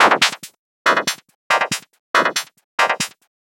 tx_synth_140_clusterchop2.wav